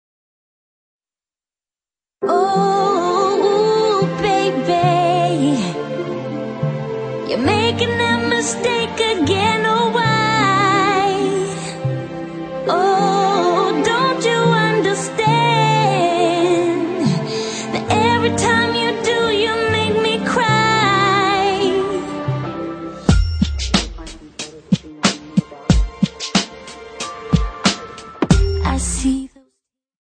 Pop & Rock